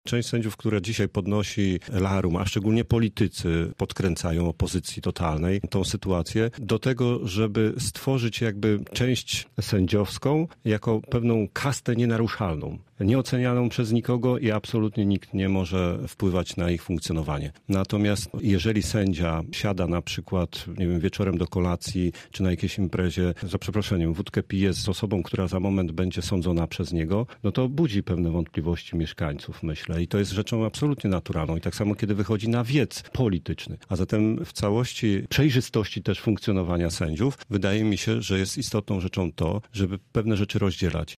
Przewodniczący Rady Miasta, w Rozmowie Punkt 9, komentował nowelizację ustaw o ustroju sadów powszechnych i Sądzie Najwyższym, którą w piątek przyjął Sejm: